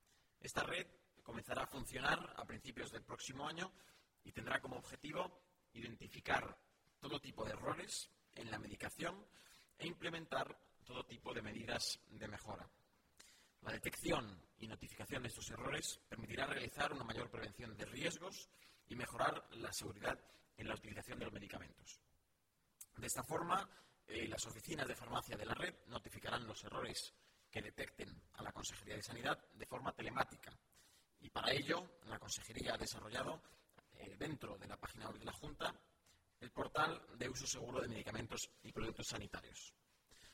El portavoz del Gobierno regional, Nacho Hernando, sobre Red Centinela:
corte1._portavoz._red_centinela.mp3